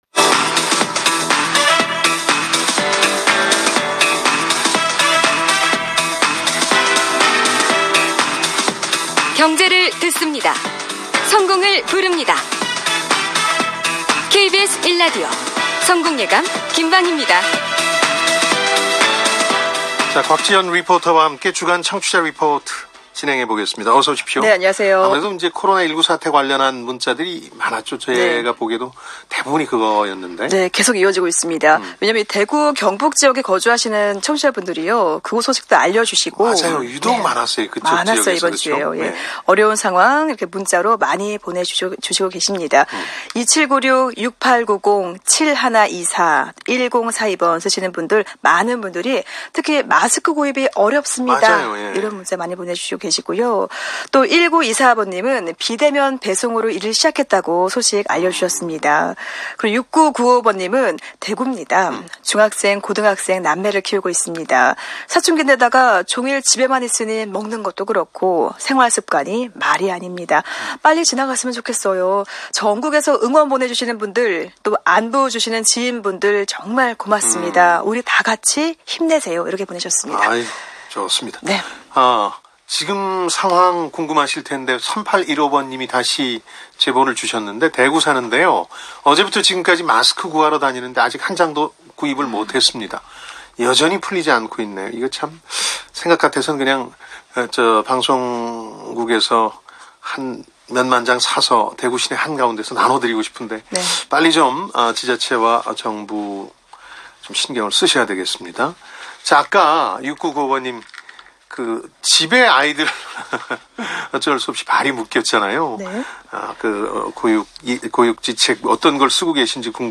집 밖 출입이 무서웠던 지난 코로나 속 2월의 인터뷰다.